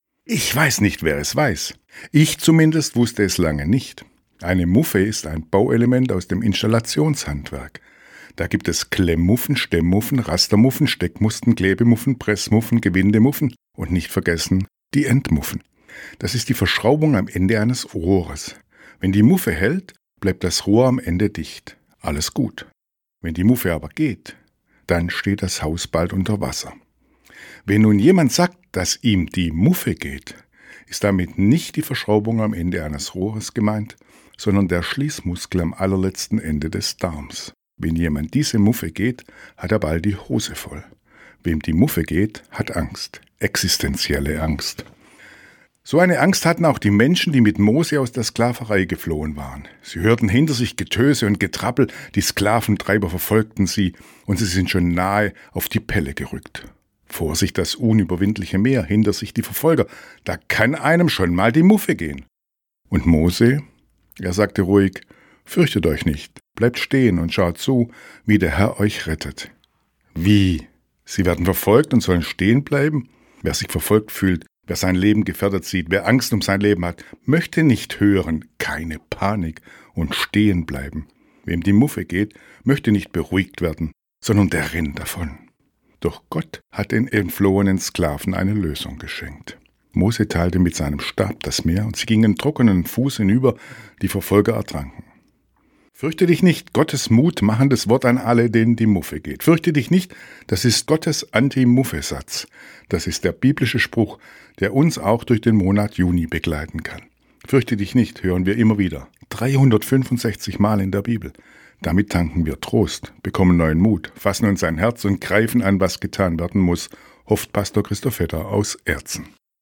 Radioandacht vom 6. Juni